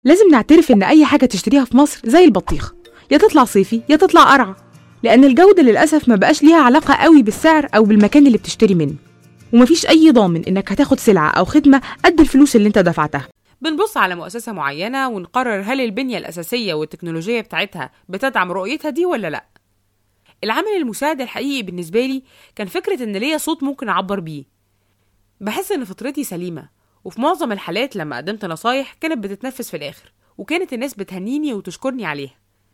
Mısır Arapçası Seslendirme
Kadın Ses